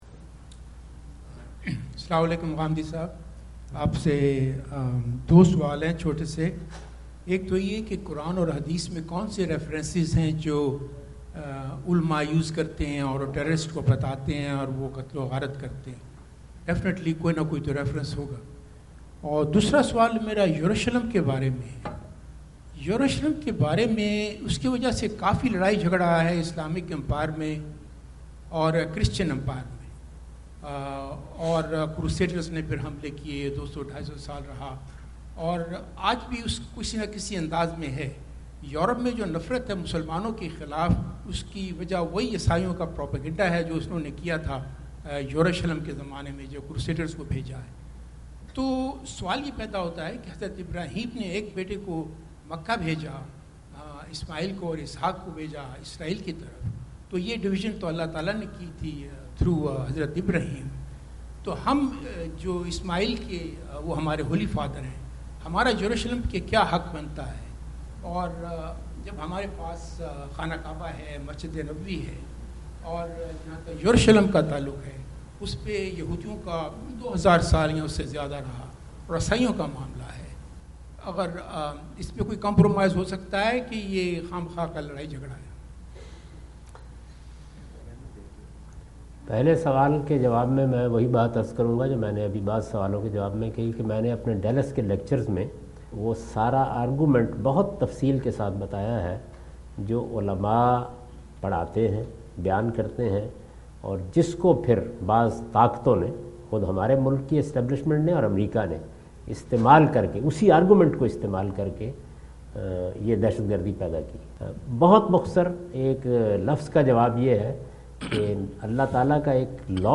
Javed Ahmad Ghamidi answer the question about "Jerusalem Controversy and its Reality" During his US visit in Dallas on October 08,2017.
جاوید احمد غامدی اپنے دورہ امریکہ2017 کے دوران ڈیلس میں "یروشلم کا تنازعہ اور اسکی حقیقت" سے متعلق ایک سوال کا جواب دے رہے ہیں۔